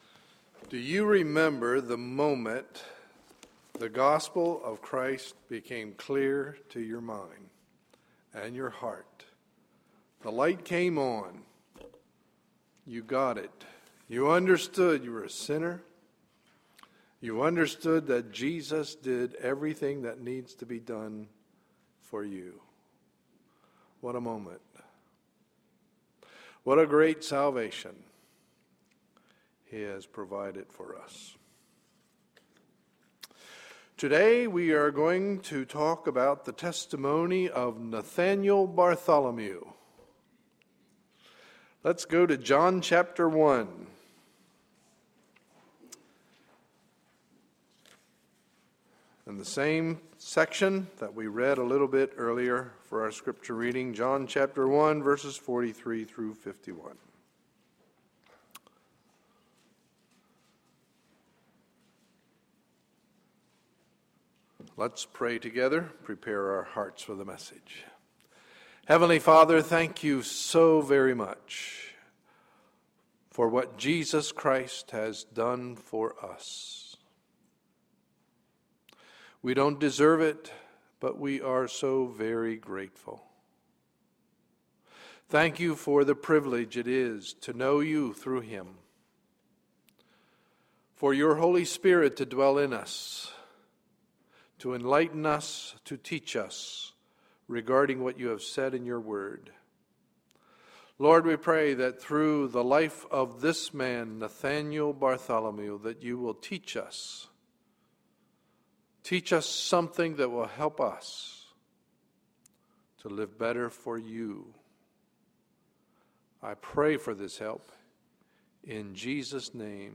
Sunday, July 8, 2012 – Morning Message